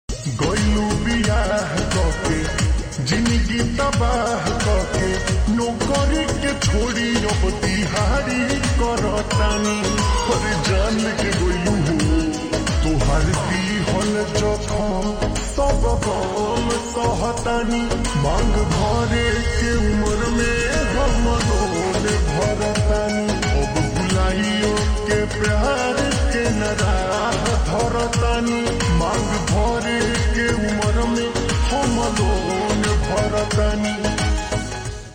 Bhojpuri Songs
• Simple and Lofi sound
• Crisp and clear sound